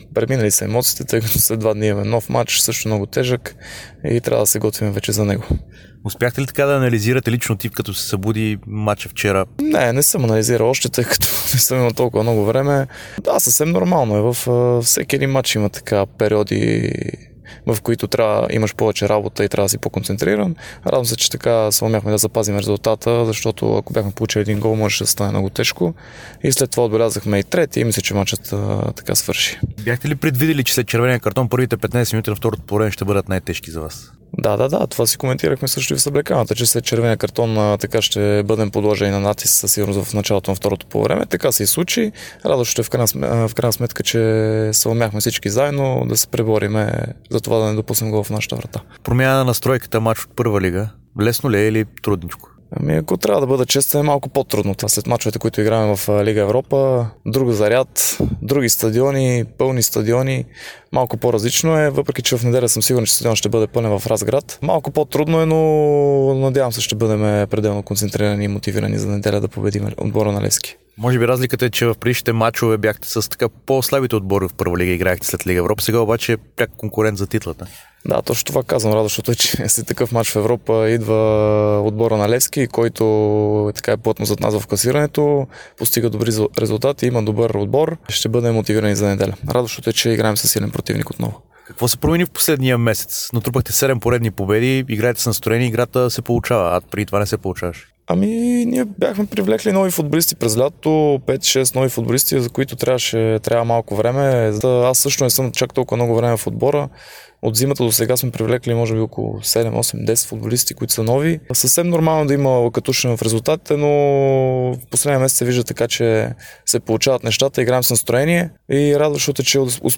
Вратараят на Лудогорец Пламен Илиев даде специално интервю пред dsport ден след двубоя срещу Ференцварош и два дни преди домакинството срещу Левски.